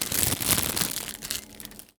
R - Foley 65.wav